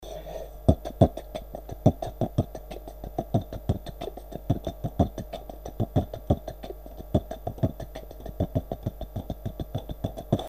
Зацените пару битов оч маленьких небольших)))) дополните если что)правда качесто из за микорфона плохое(
во 1 там не киска))) там кэс))))) ага за место снэра
Помоему во 2 записи кэс нормально звучит а вот в 1 хендклэп плохой и нечего смешного тут нету!!!!!
Херово звучит и то и то)